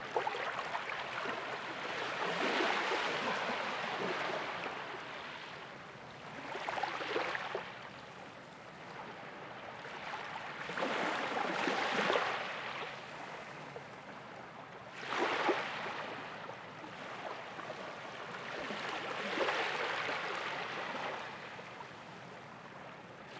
SC Beach Loop 4.ogg